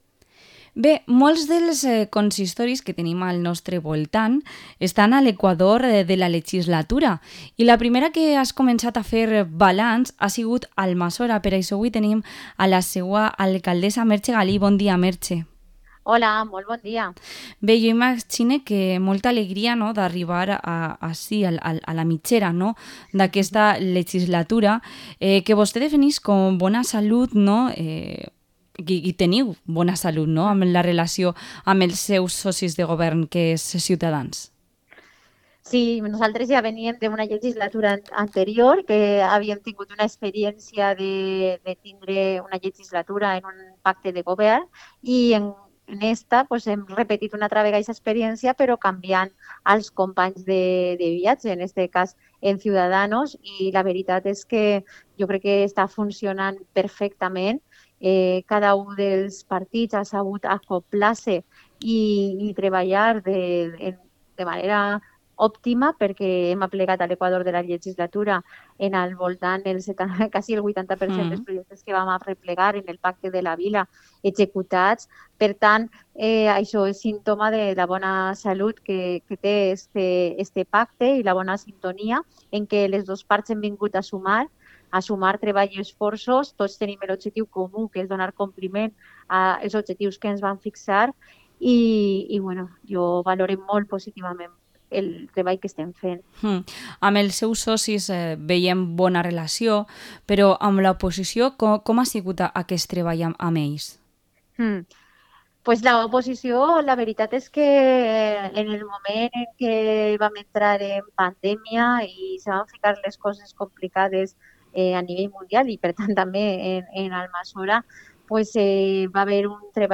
Entrevista a la alcaldesa de Almassora, Merche Galí